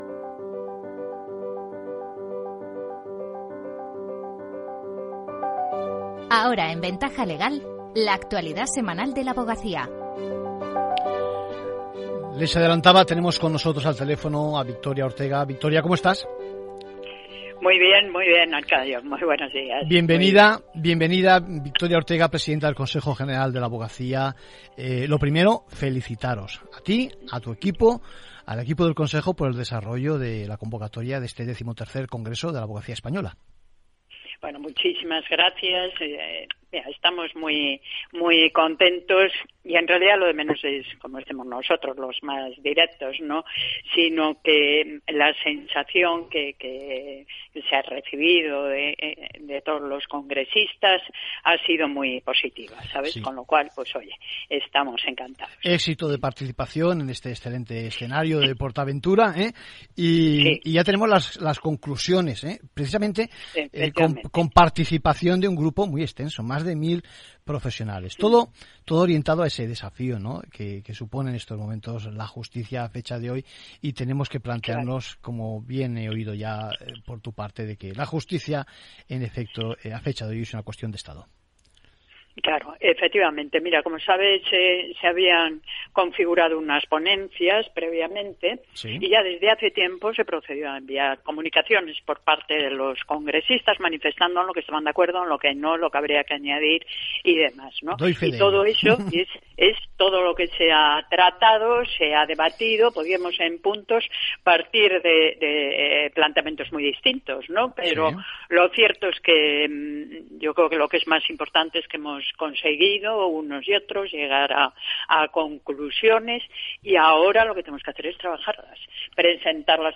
Entrevista a Victoria Ortega en Capital Radio tras el XIII Congreso de la Abogacía Española